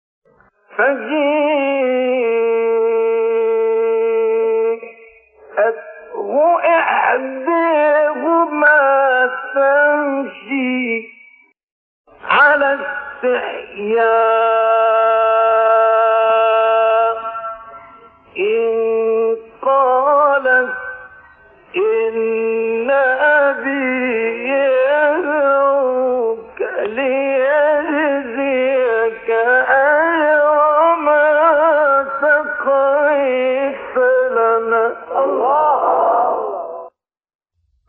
گروه شبکه اجتماعی: 10 مقطع صوتی از قاریان برجسته مصری که در مقام رست اجرا شده‌ است، می‌شنوید.
مقام رست